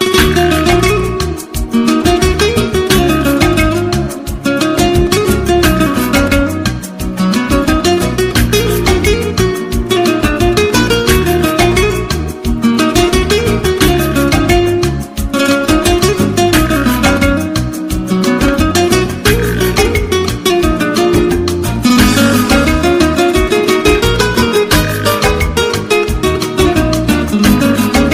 Category: Spanish Ringtones